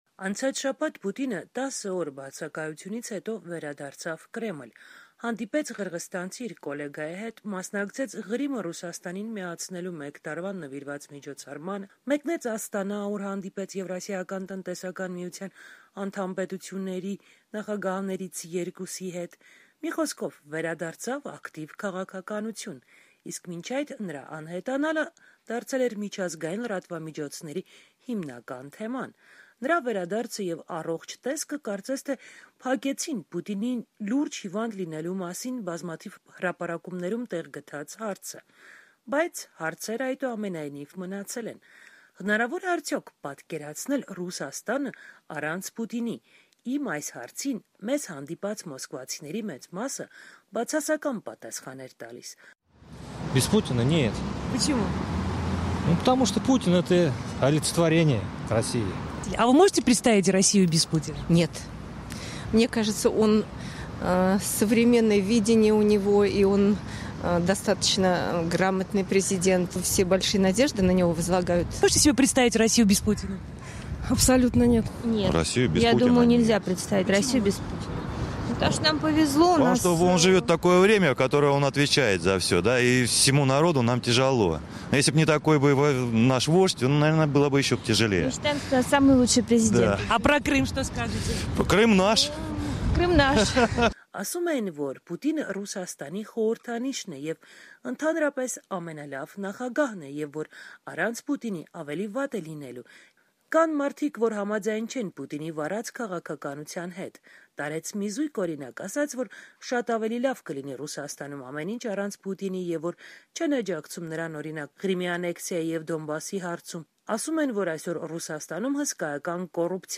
Ռուսաստանցիները պատասխանում են «Ազատության» հարցին, թե արդյոք պատկերացնում են Ռուսաստանն առանց Պուտինի: Մեր թղթակցին հանդիպած քաղաքացիների մեծ մասը չէր պատկերացնում երկիրն առանց ներկայիս նախագահի: